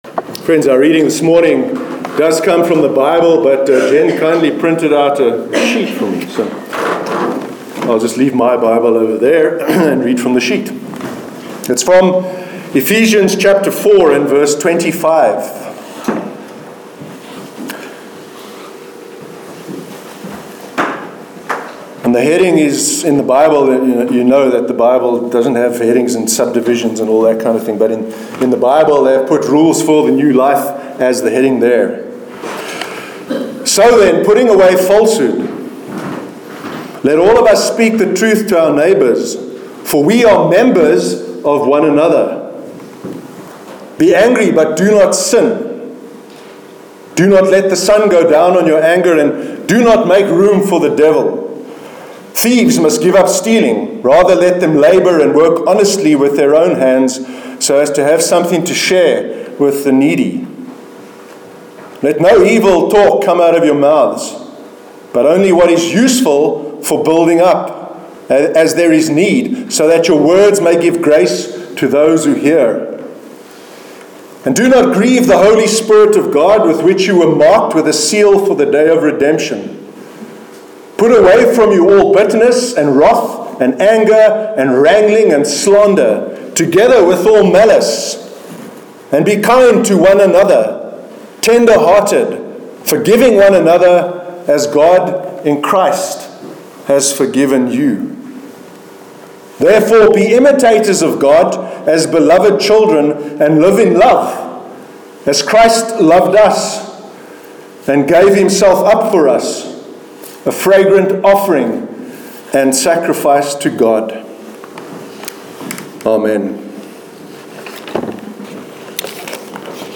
Sermon on Sacrifice- 26th Feb 2017